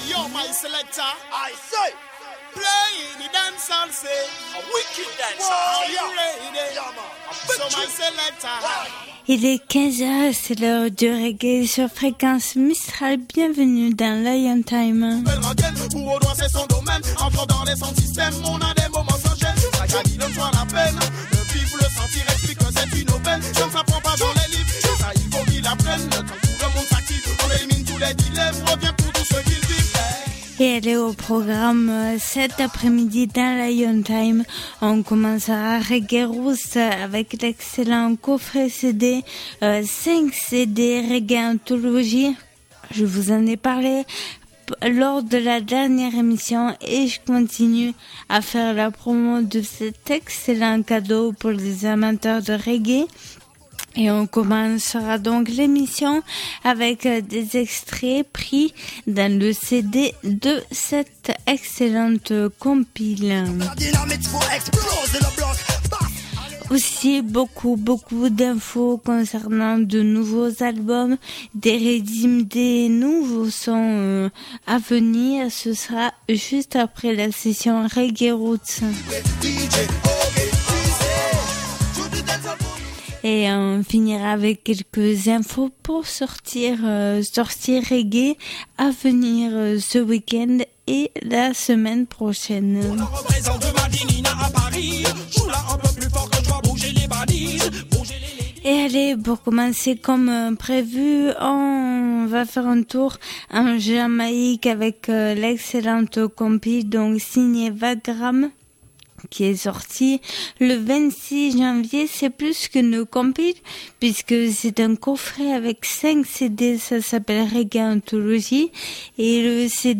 Émission qui invite au voyage et à la découverte du roots du reggae et du dancehall. Que vous soyez novices ou grands connaisseurs de la musique reggae et la culture rasta, cette émission permet d’écouter de la bonne musique et surtout des artistes de qualité qui sont très rarement programmés sur d’autres radios.